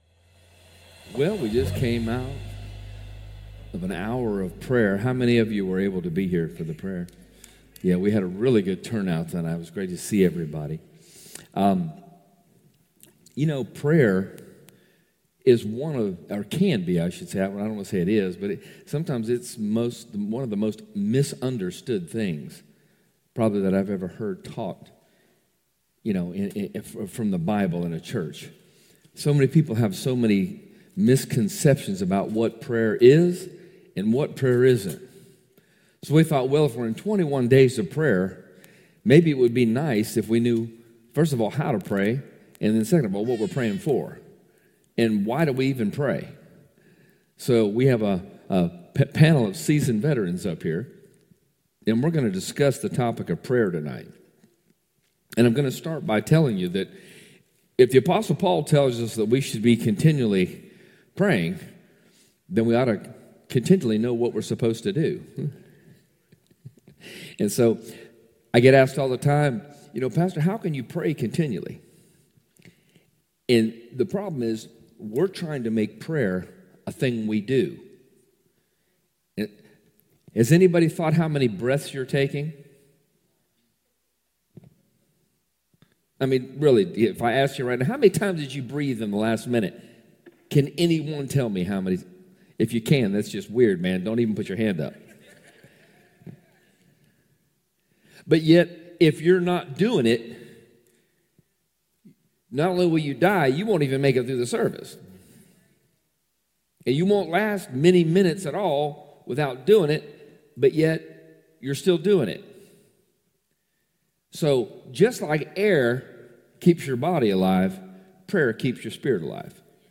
1 Heart to Heart - Panel Discussion